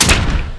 fire_missile_long.wav